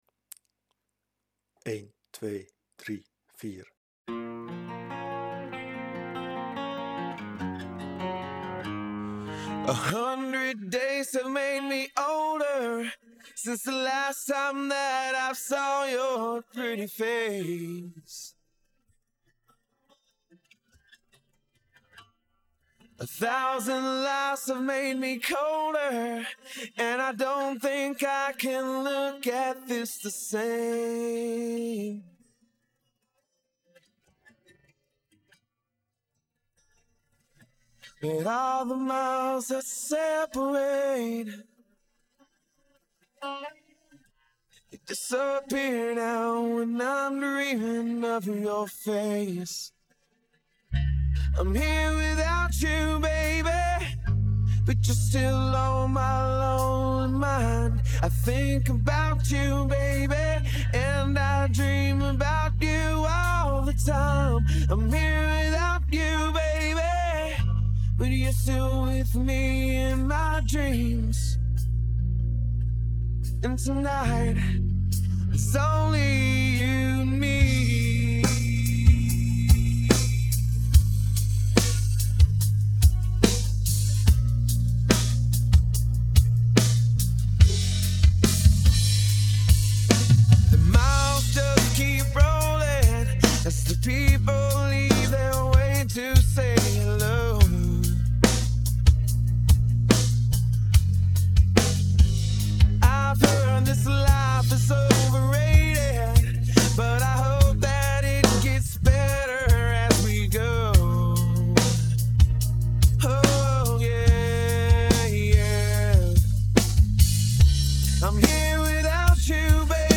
The backingtrack starts with four beats.